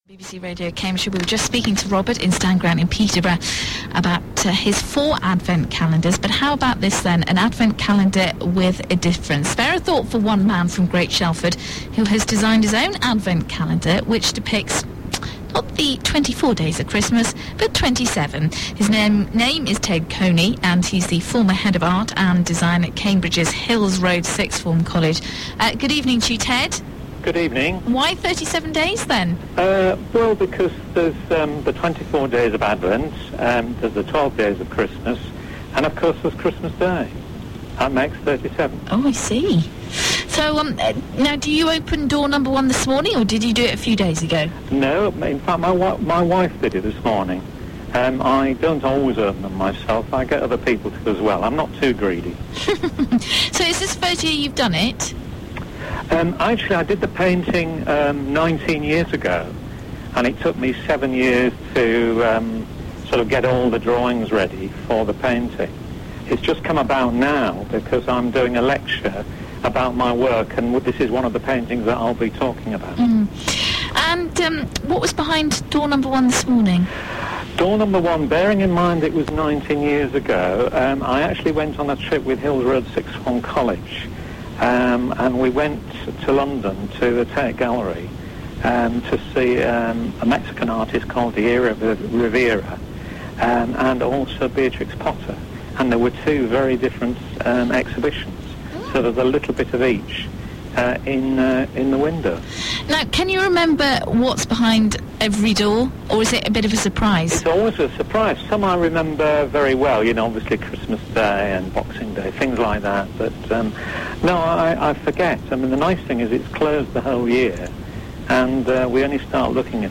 37 Days of Christmas radio interview 2005